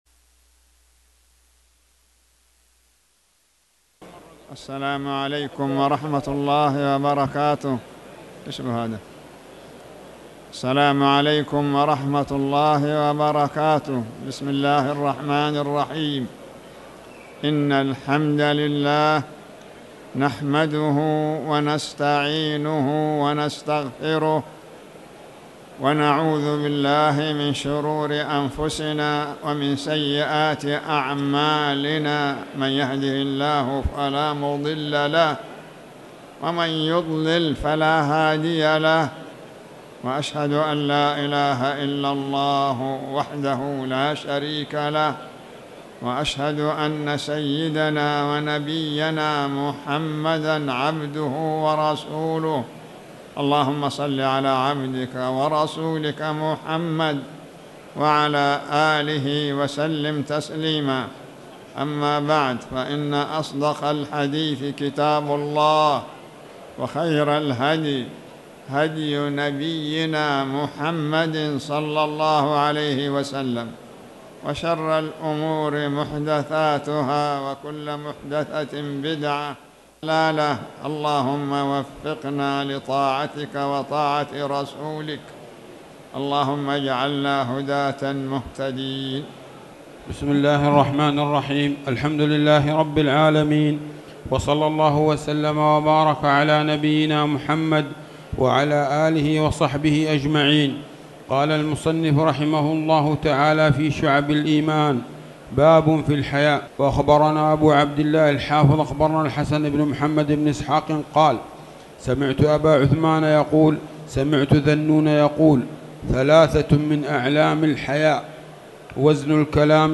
تاريخ النشر ١٥ ربيع الثاني ١٤٣٩ هـ المكان: المسجد الحرام الشيخ